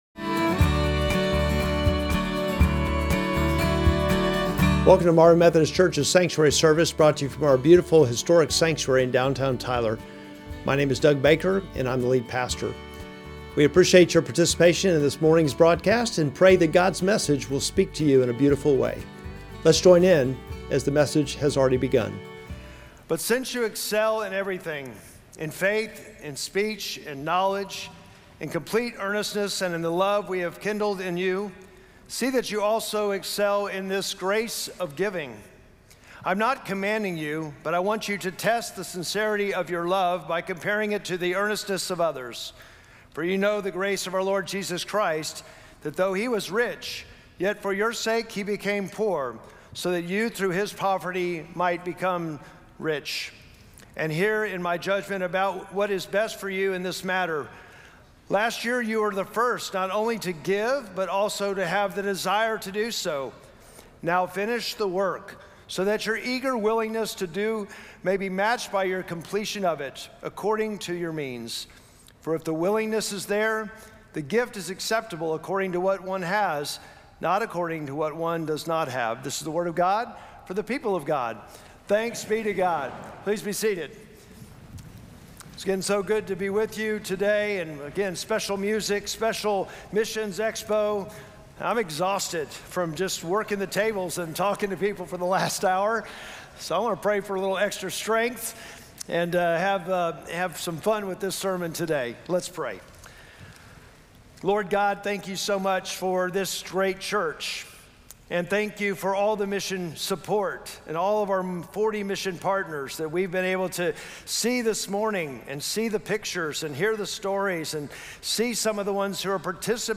Sermon text: 2 Corinthians 8:7-12